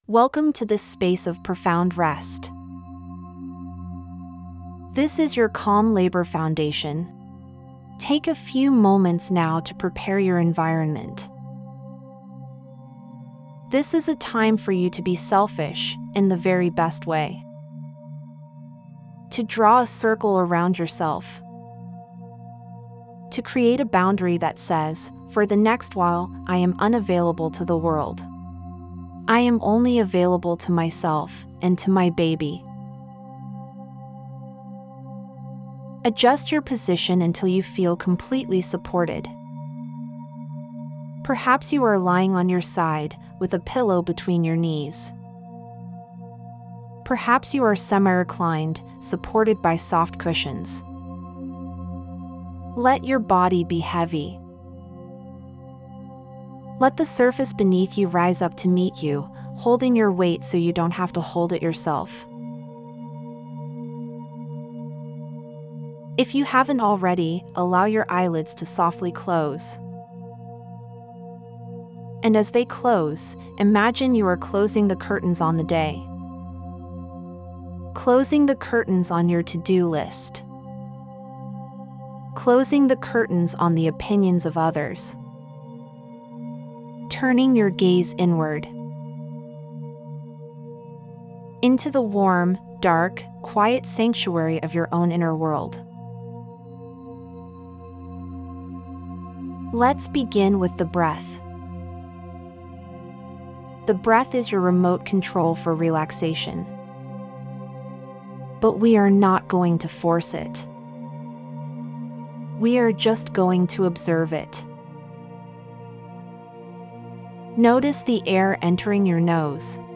Calm Labour Foundation — Hypnobirthing Guided Session | Hypnobirthing+ · Hypnobirthing+